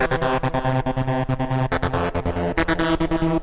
描述：melody made with reFX Vanguard. sound one.
声道立体声